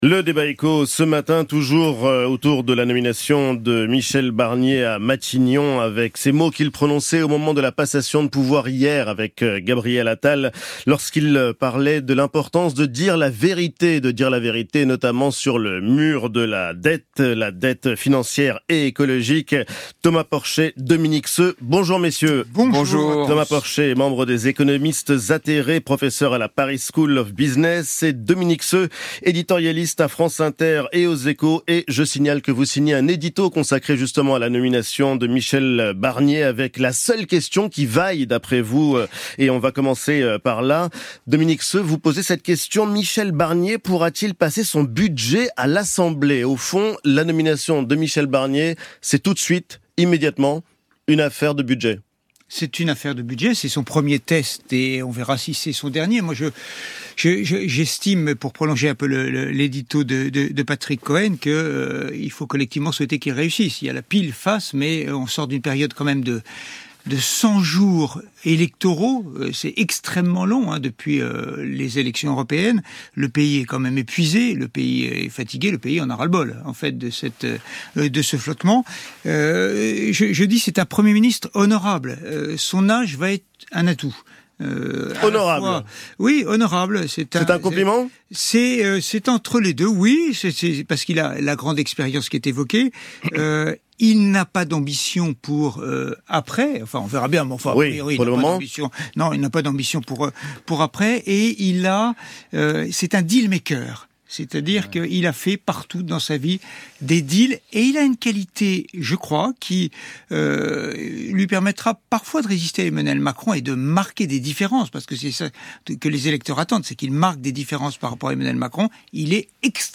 Débat éco avec Dominique Seux et Thomas Porcher : La France face au mur de la dette